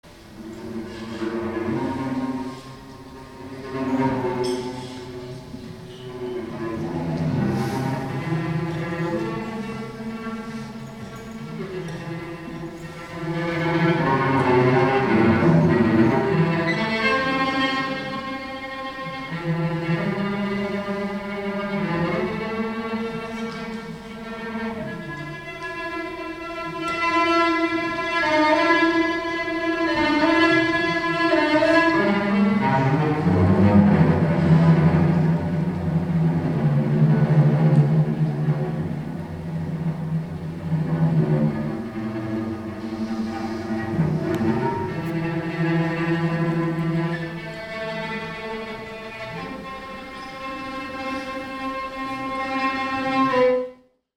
Lesereise mit deutsch-polnischem Poesieprogramm
Cello